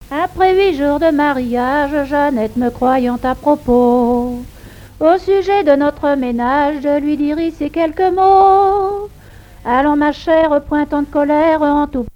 Genre strophique
collecte en Vendée
répertoire de chansons traditionnelles
Pièce musicale inédite